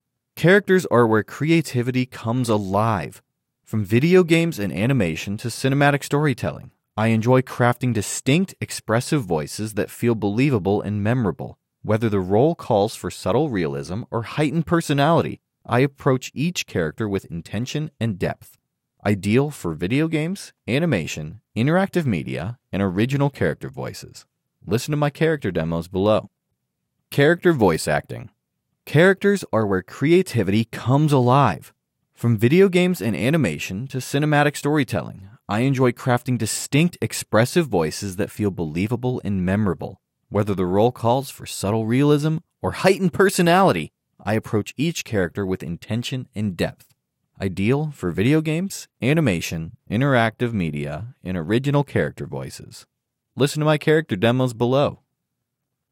Characters are where creativity comes alive.
• Original character voices
Narration-Character.m4a